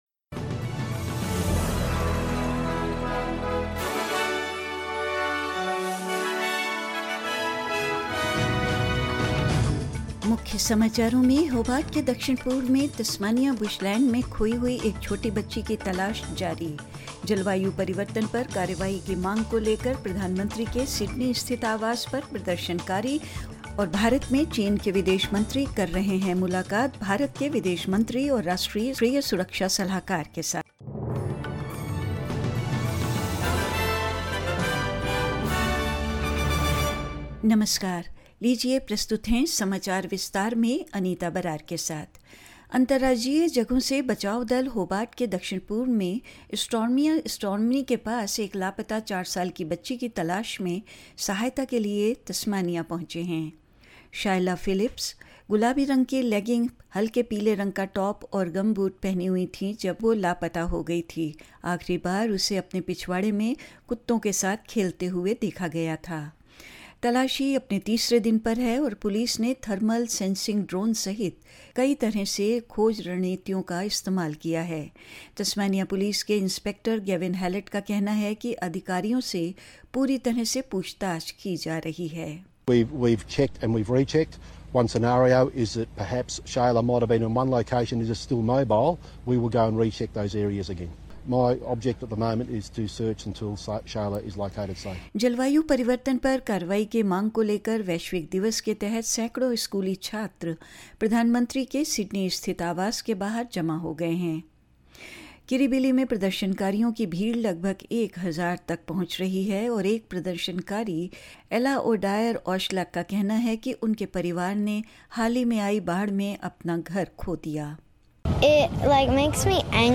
In this latest SBS Hindi bulletin: The search continues for a little girl lost in Tasmanian bushland south-east of Hobart; Hundreds of school students gathered outside the prime minister's Sydney home demanding action on climate change; Chinese Foreign Minister Wang Yi meets his counterpart S Jaishankar and national security advisor Ajit Doval In New Delhi, India and more news.